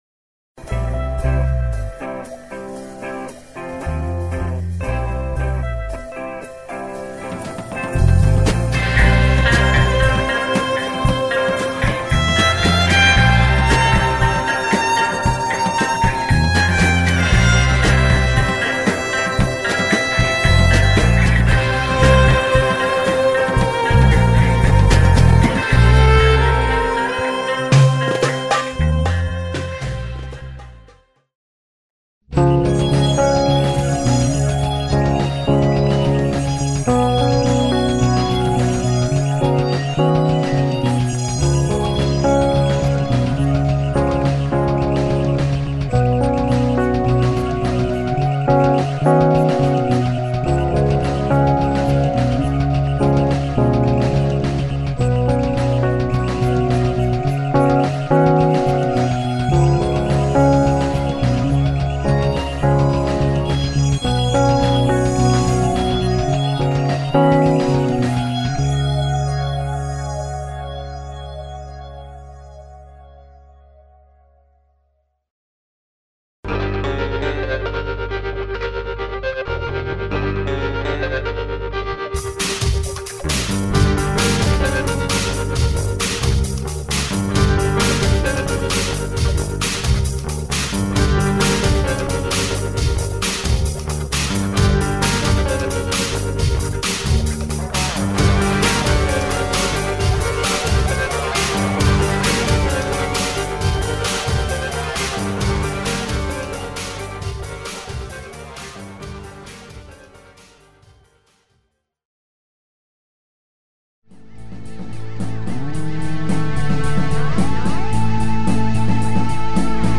sound reel